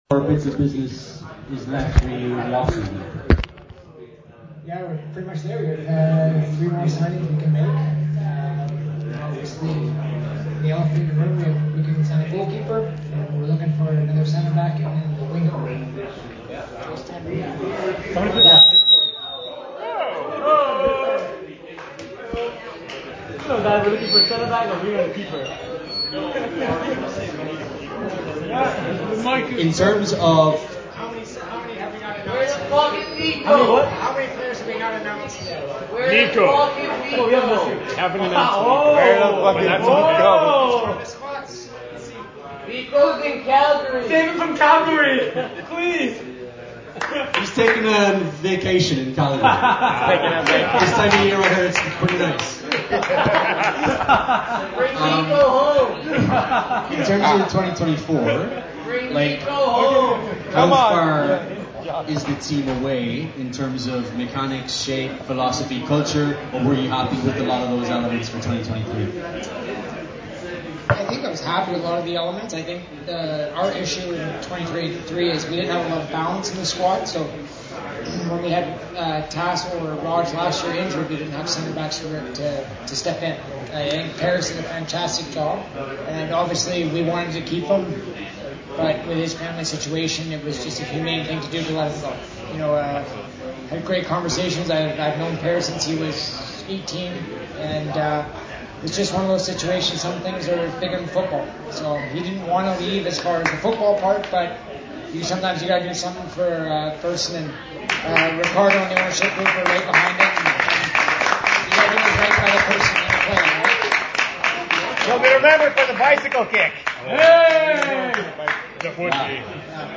2024...York United FC Fan's Meet and Greet Event at the Elephant & Castle in Toronto